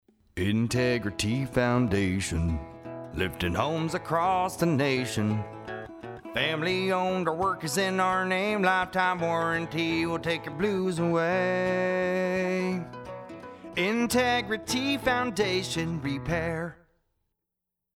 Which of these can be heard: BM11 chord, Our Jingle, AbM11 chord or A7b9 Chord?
Our Jingle